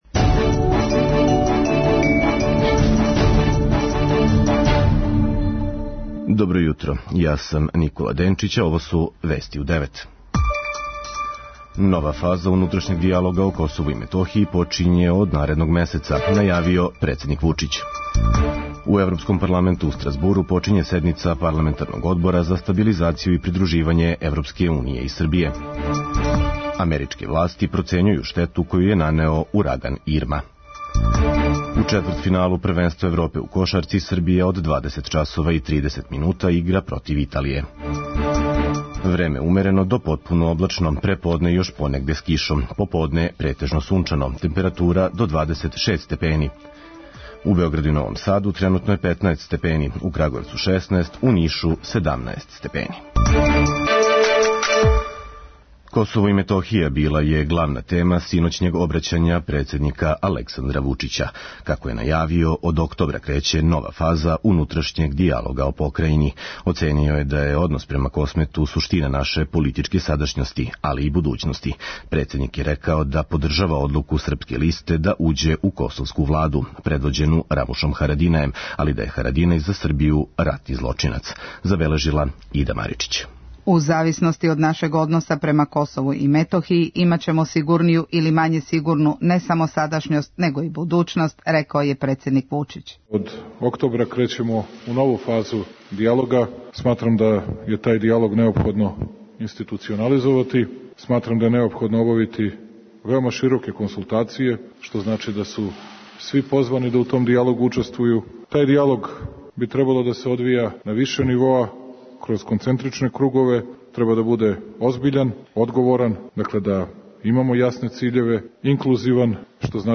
преузми : 3.40 MB Вести у 9 Autor: разни аутори Преглед најважнијиx информација из земље из света.